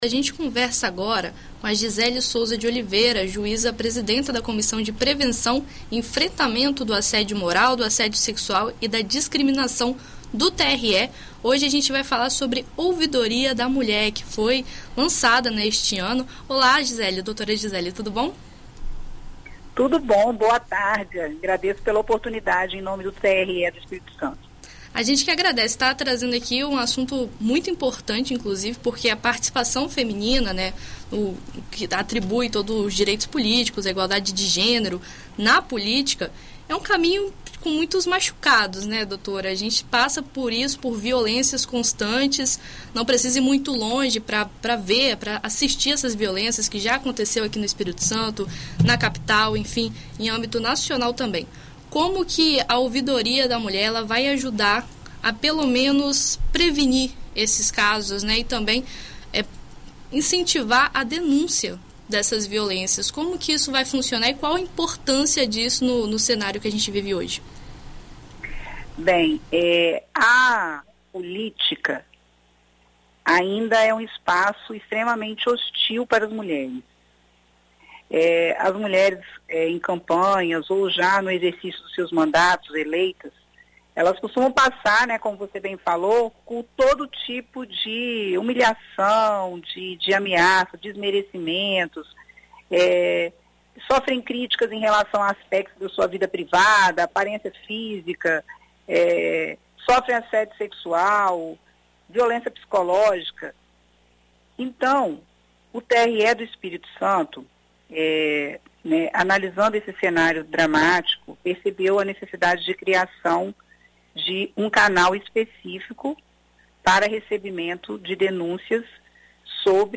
Em entrevista à BandNews FM Espírito Santo nesta terça-feira (30),a juíza presidenta da Comissão de Prevenção e Enfrentamento Moral, do Assédio Sexual e da Discriminação no 1º grau de Jurisdição do TRE-ES, Gisele Souza de Oliveria, explica como funciona a ouvidoria.